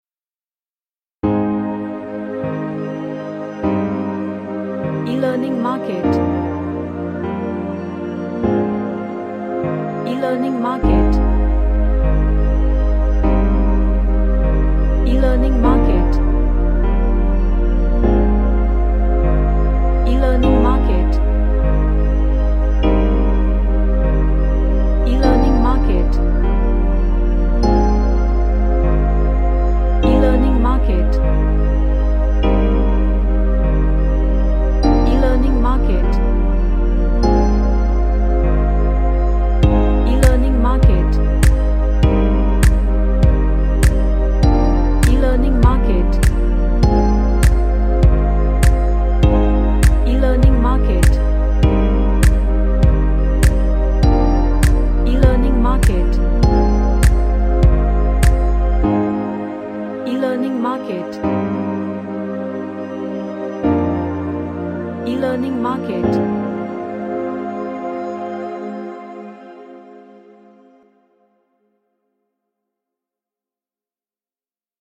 An Ambient Track with relaxing Tone.
Relaxation / Meditation